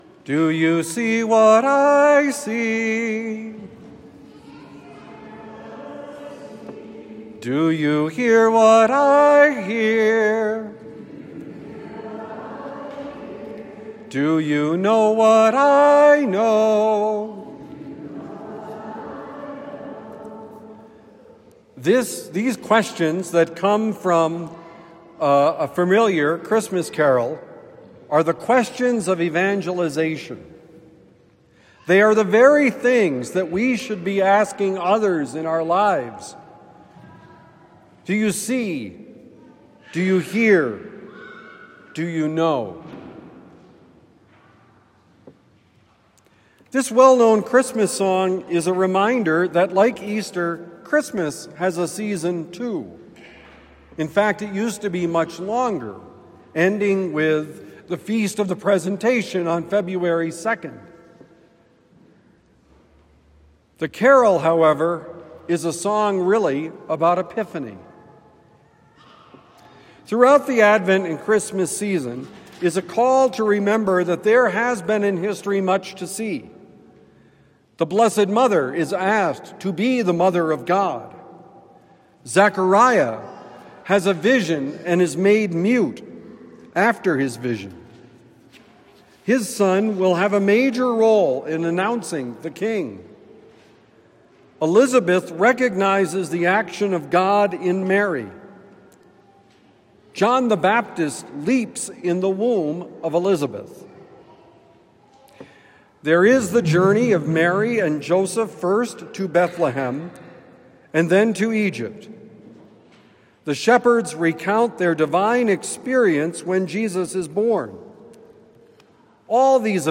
Do you see what I see? Homily for Sunday, January 4, 2026